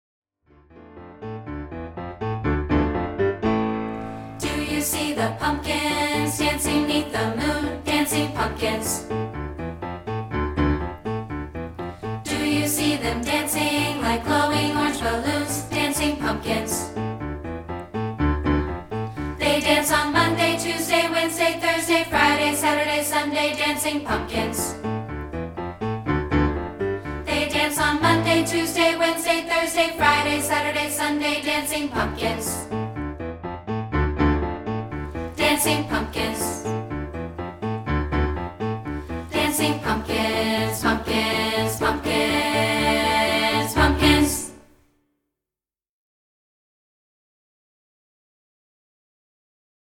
Here's a rehearsal track of part 2, isolated
spooky song